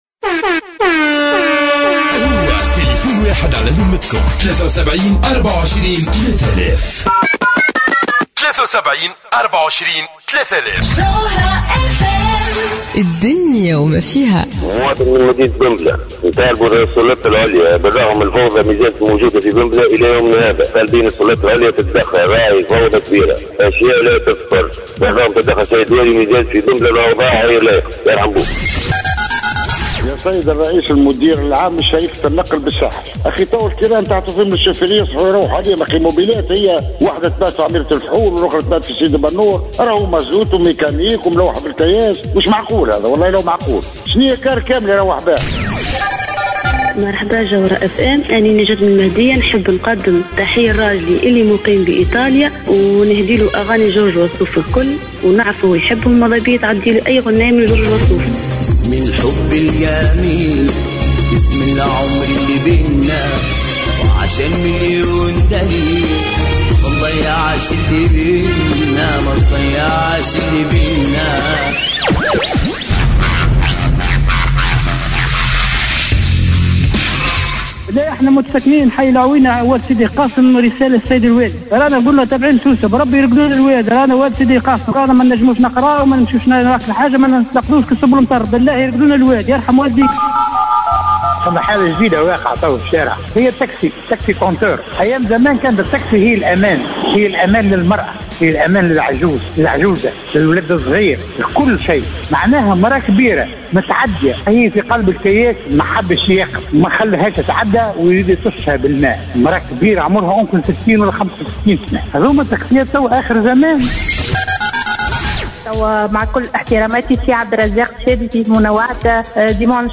Une auditrice adresse à message à Radhia Nasraoui